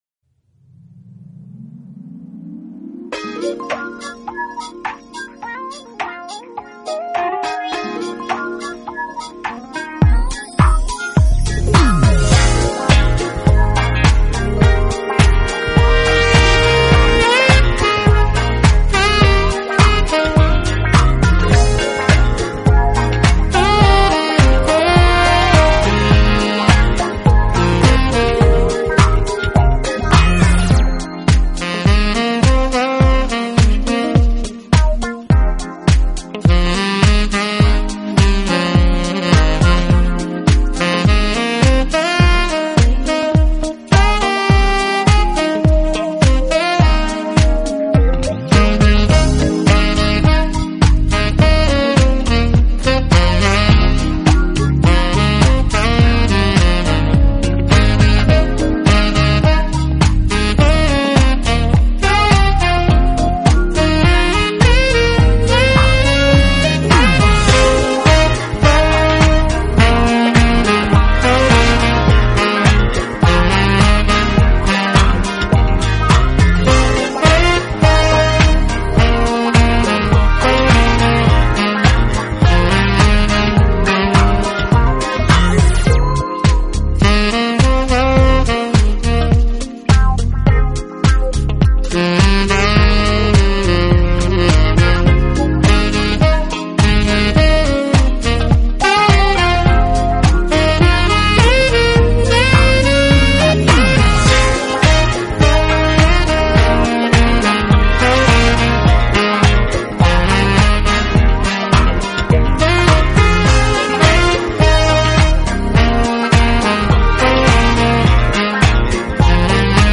Genre: Jazz, Smooth Jazz, World Fusion, Saxophone
saxophone, alto saxophone, tenor saxophone
trumpet, trombone, keyboards, drum programming